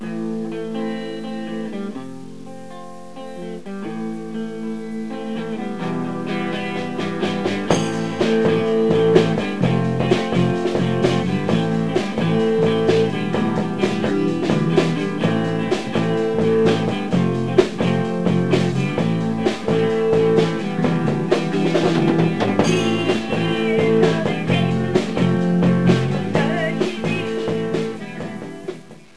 Ce premier exemple introduit une chanson avec des arpèges sur la grille (suite d'accords) des couplets.
LAm | FA | LAm | MIm
L'introduction comporte deux fois ce cycle. La première fois, il n'y a que la guitare qui joue les arpèges ci-dessous. La seconde fois, tous les intruments jouent et la guitare fait la ryhtmique avec les accords de la grille.